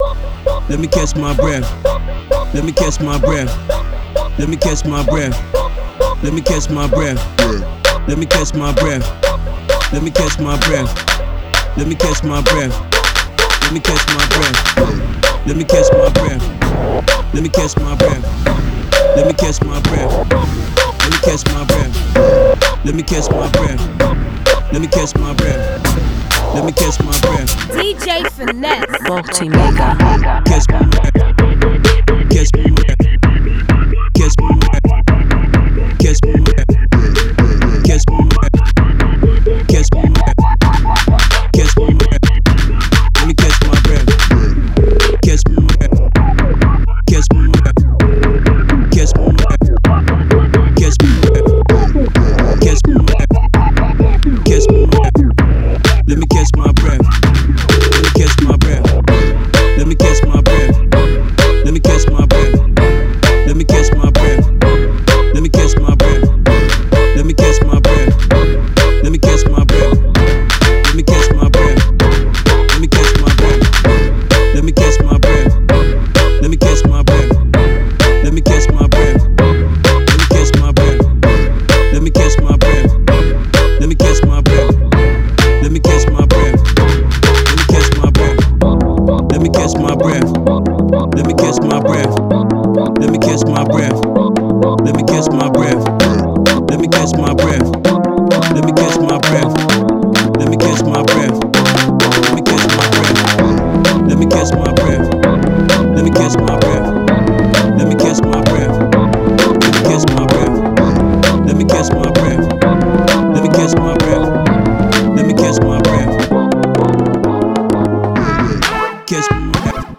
Edm
Baltimore Club